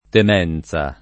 temenza [ tem $ n Z a ] s. f.